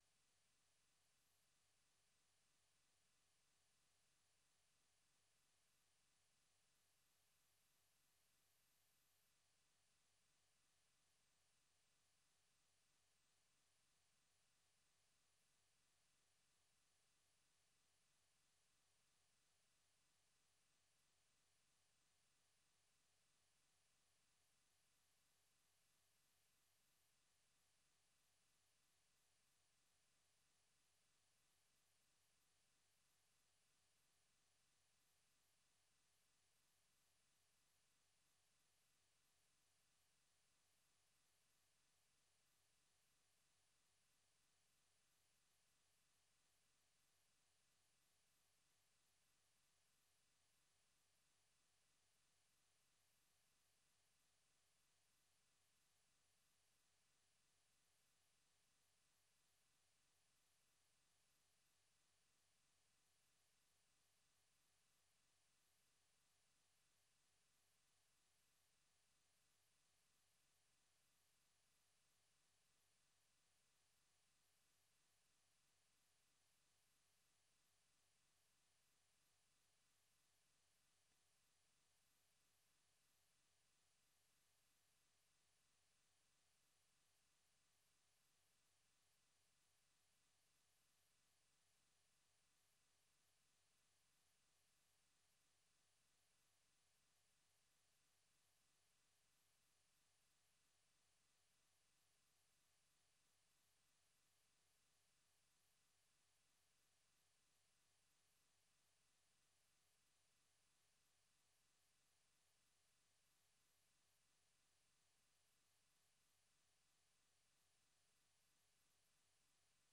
Matangazo ya nusu saa kuhusu habari za mapema asubuhi pamoja na habari za michezo.